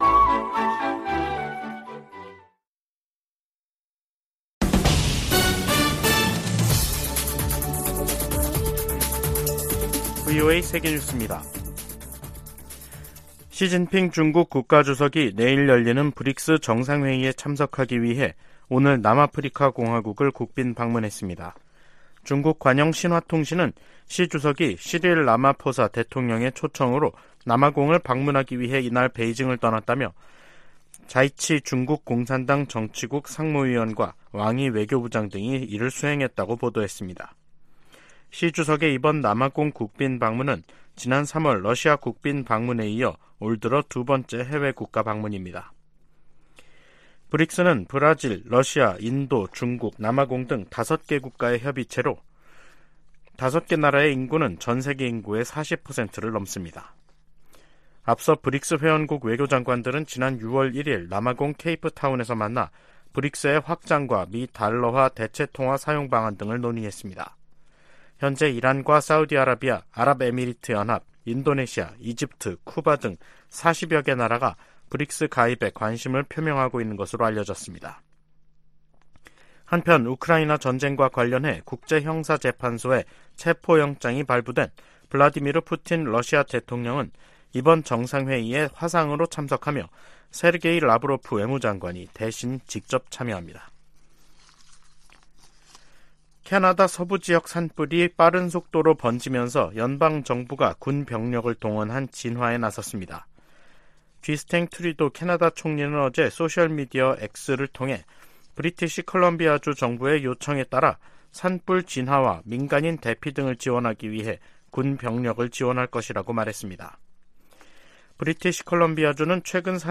VOA 한국어 간판 뉴스 프로그램 '뉴스 투데이', 2023년 8월 21일 2부 방송입니다. 미국과 한국, 일본 정상들은 18일 채택한 캠프 데이비드 정신에서 3국 정상 회의를 연 1회 이상 개최하기로 합의했습니다. 윤석열 한국 대통령은 북한의 도발 위협이 커질수록 미한일 안보 협력은 견고해질 것이라고 밝혔습니다. 조 바이든 미국 대통령은 우크라이나에서와 같은 사태가 아시아에도 벌어질 수 있다고 경고하며 안보 협력 중요성을 강조했습니다.